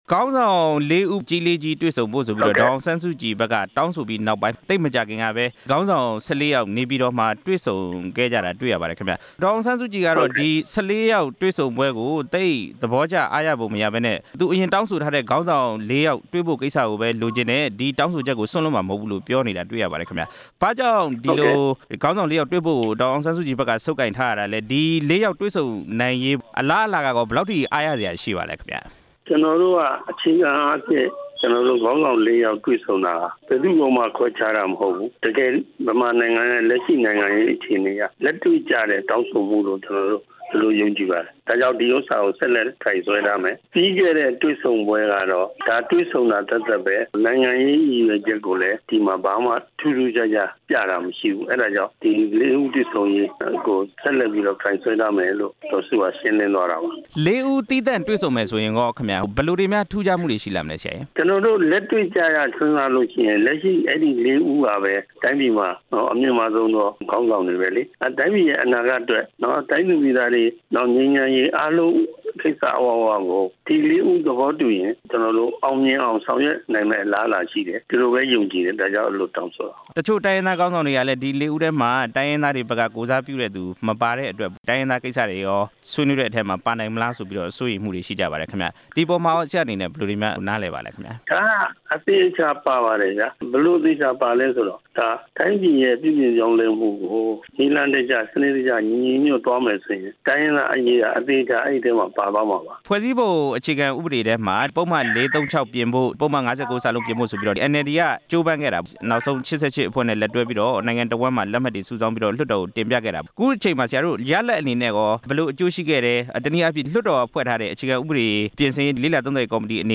ခေါင်းဆောင် ၁၄ ဦး တွေ့ဆုံပွဲ အပြီး NLD ပါတီ ရှေ့လုပ်ငန်းစဉ် မူဝါဒ မေးမြန်းချက်